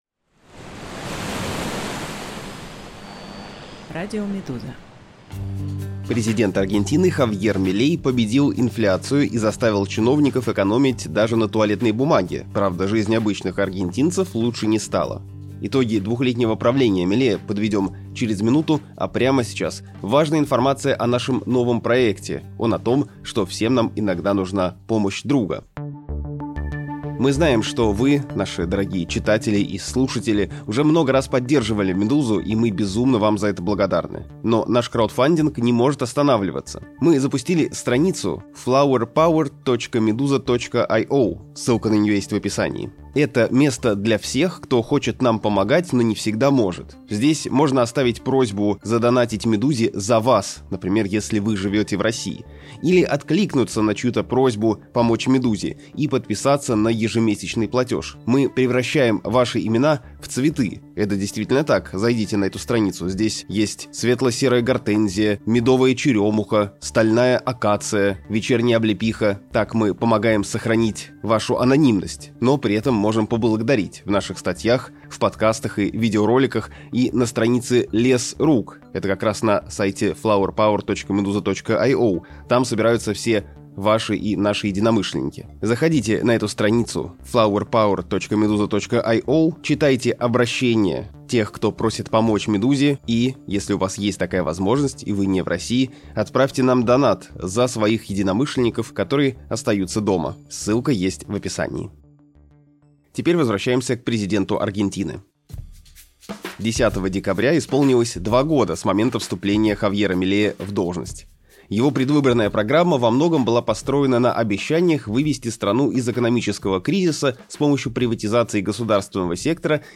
Рассказываем, какими получились первые два года президентства Милея и что ждет Аргентину, пока он руководит страной. Аудиоверсию этого текста слушайте на «Радио Медуза» подкасты Два года президентства Хавьера Милея в Аргентине.